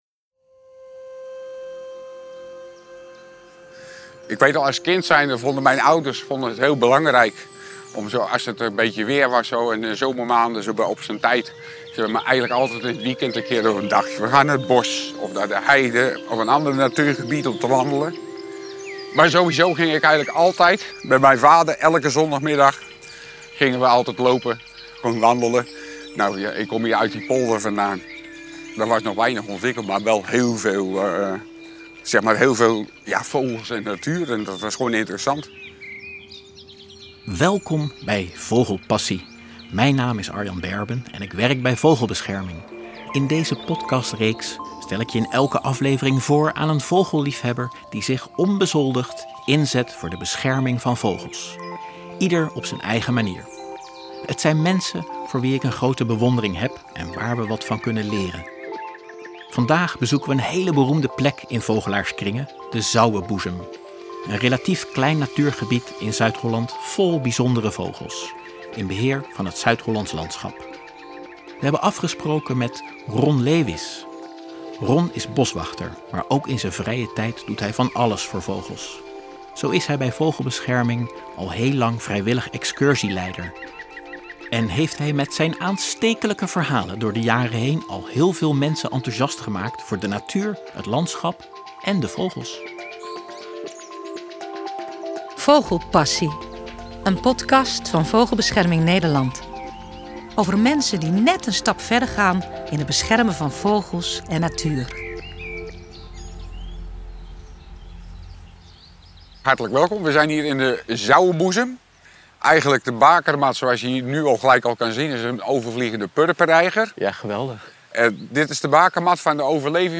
Het is een bakermat van de purperreiger en geliefd onder vogelaars om die reden - al is er nog veel meer te zien en te horen, zoals we al wandelend door het gebied waarnemen.